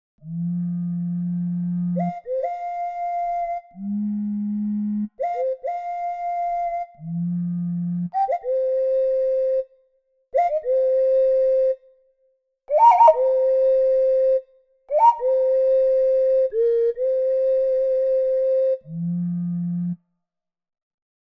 Ocarinas and Clay Flutes for Kontakt and Reason includes the haunting sounds of the native American flutes of north and central America. Four different clay flutes and three ocarinas, played so that they create sweet or sorrowful, straight, vibrato, or wild calls, screeches, or tonal or microtonal trills, or bird calls.
Solo Flute 3
Solo_Flute_Demo_3.mp3